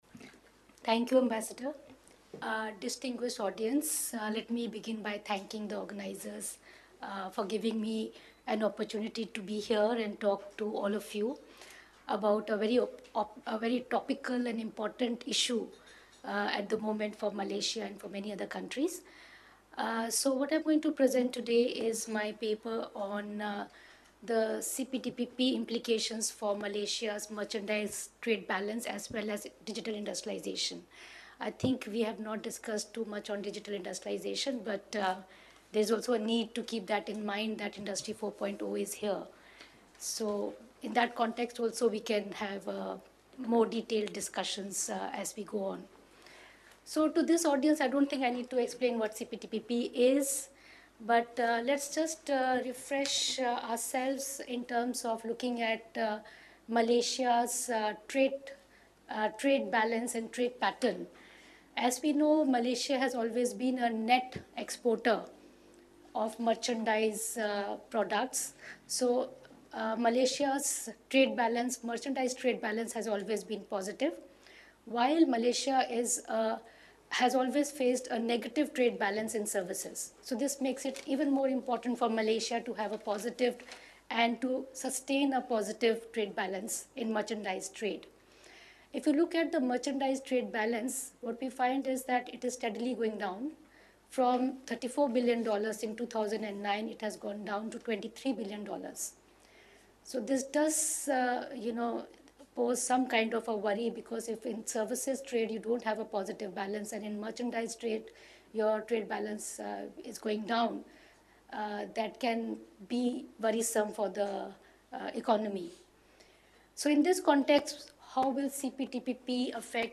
IIAF_on_CPTPP_Presentations_14_June.mp3